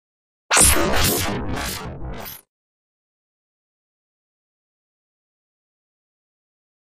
Robot High Frequency Robot Communique with Flange